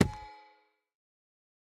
Minecraft Version Minecraft Version 25w18a Latest Release | Latest Snapshot 25w18a / assets / minecraft / sounds / block / chiseled_bookshelf / insert_enchanted3.ogg Compare With Compare With Latest Release | Latest Snapshot
insert_enchanted3.ogg